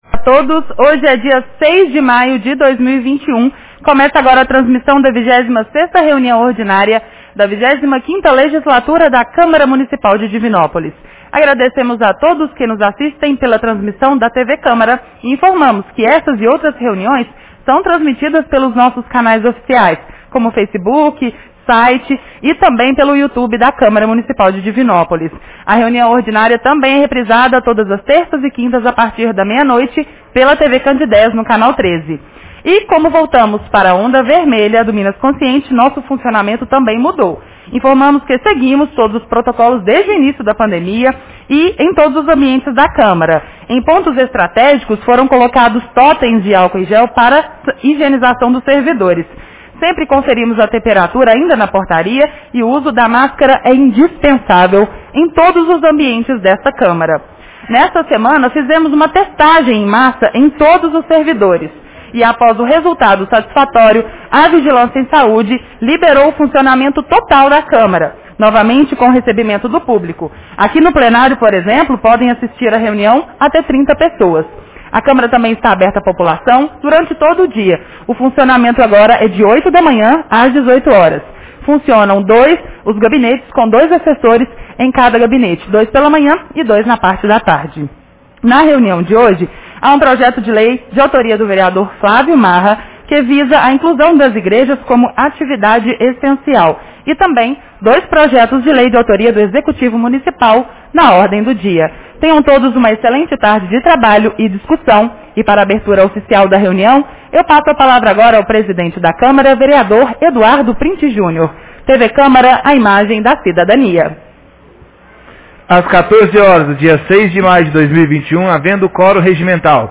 Reunião Ordinária 26 de 06 de maio 2021